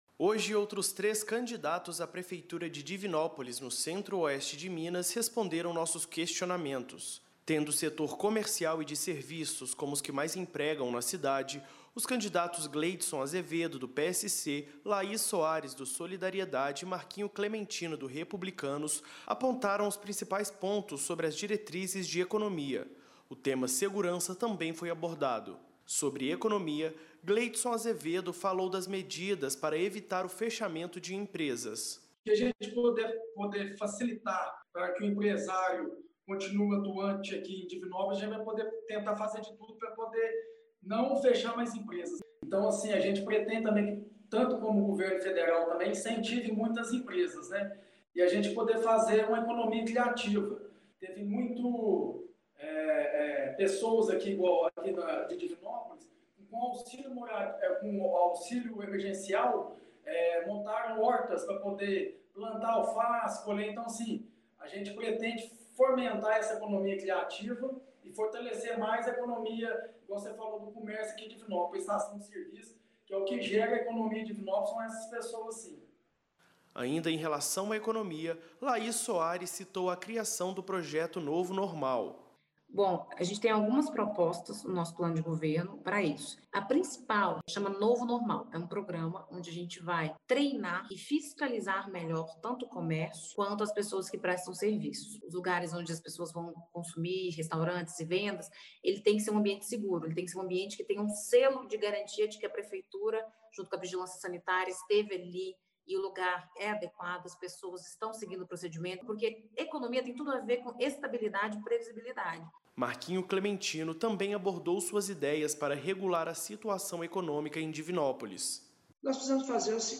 Os candidatos são os últimos entrevistados da cidade de Divinópolis